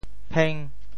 枰 部首拼音 部首 木 总笔划 9 部外笔划 5 普通话 píng 潮州发音 潮州 pêng5 文 中文解释 屏 <动> 退避;隐退 [withdraw and keep off;go and live in seclusion] 侯生乃屏人间语。